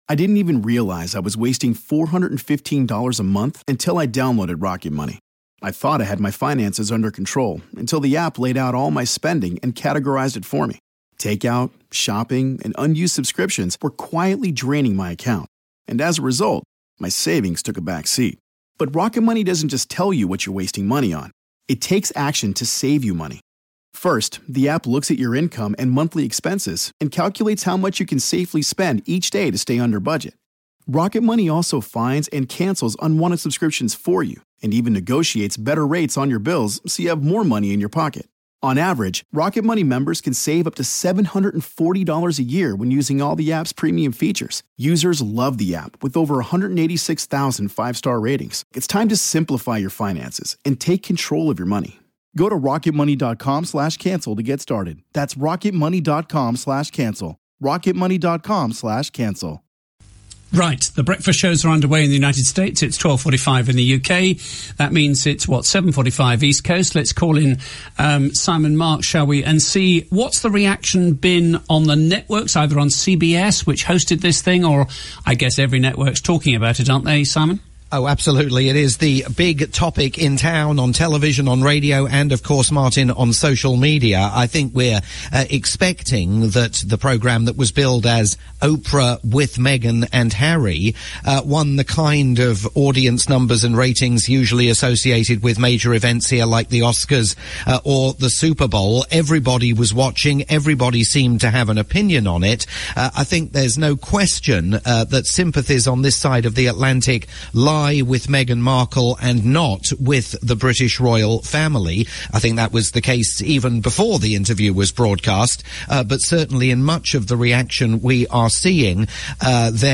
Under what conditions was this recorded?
live report for LBC News